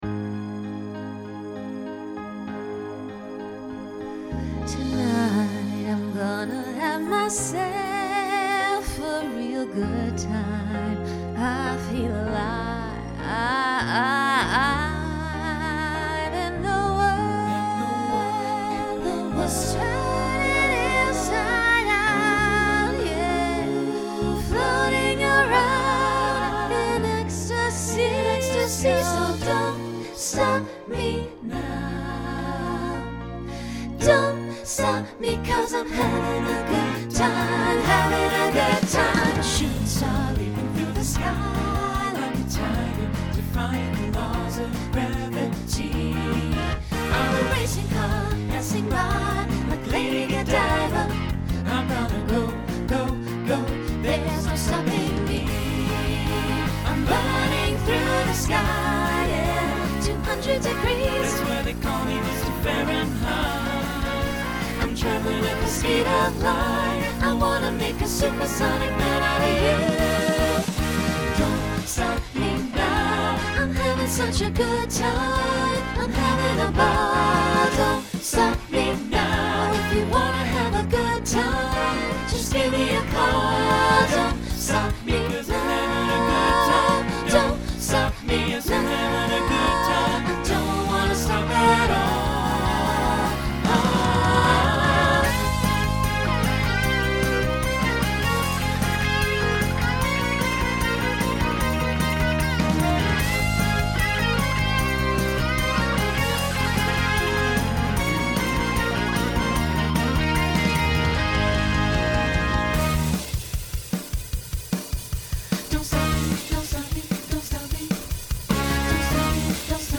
Decade 1970s Genre Rock Instrumental combo
Opener Voicing SATB